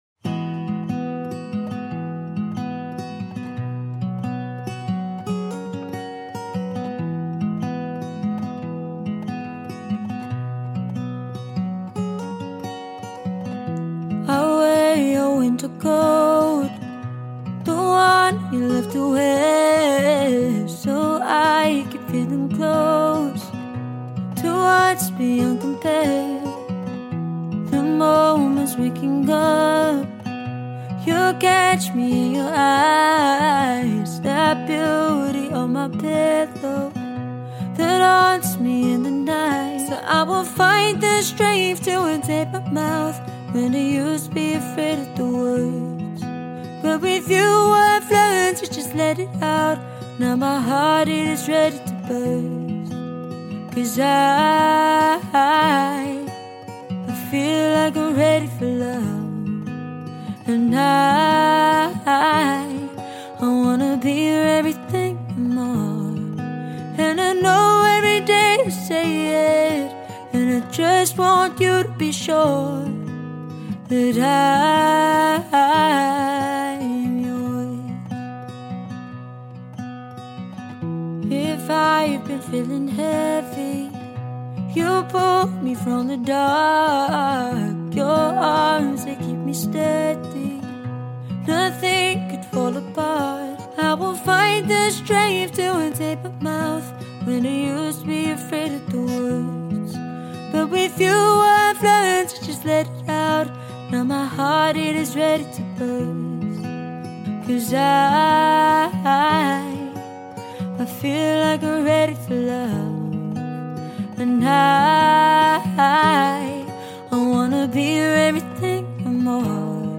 Female Solo Acoustic Guitarist for Hire